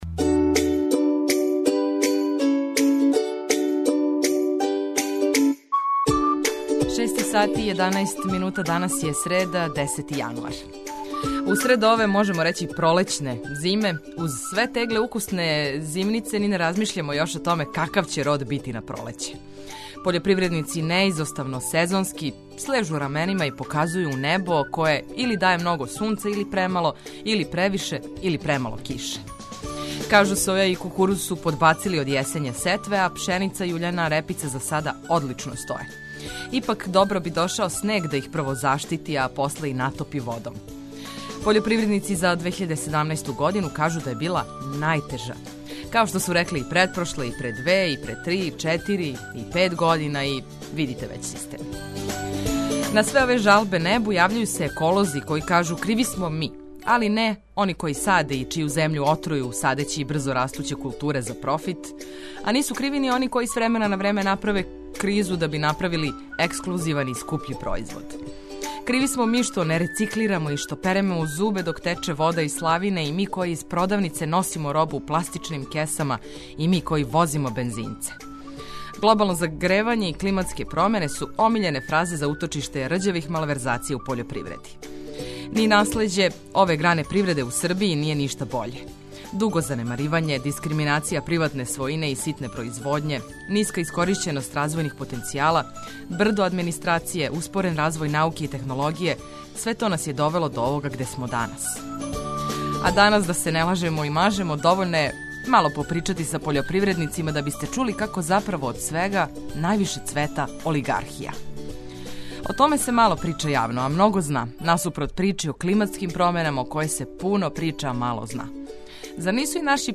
Почетак дана биће проткан корисним информацијама, музиком која разбуђује, а ту је и ведра екипа која поправља ваше расположење!